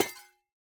Minecraft Version Minecraft Version latest Latest Release | Latest Snapshot latest / assets / minecraft / sounds / block / copper_bulb / break4.ogg Compare With Compare With Latest Release | Latest Snapshot